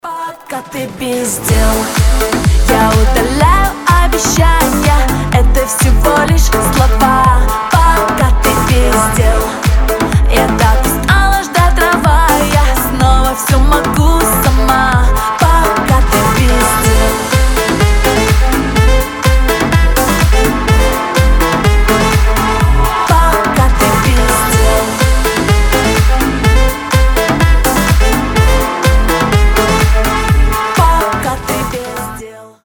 громкие
зажигательные
веселые